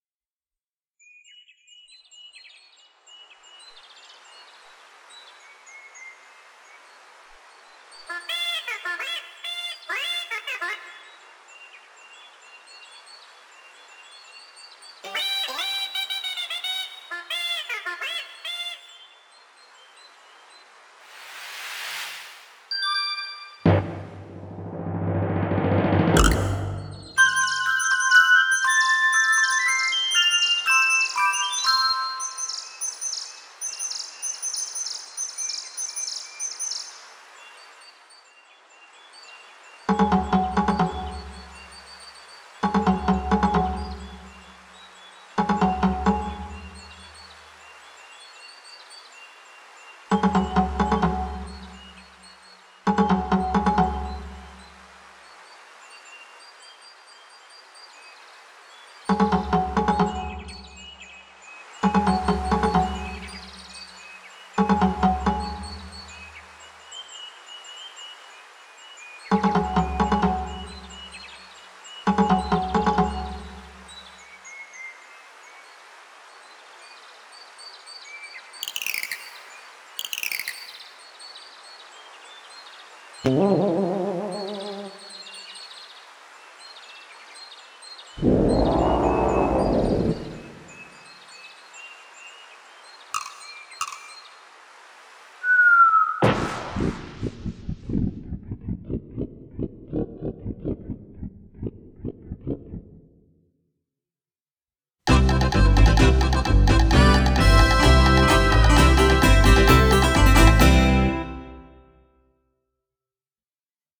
Recorded digitally, using a M-Audio FireWire Audiophile.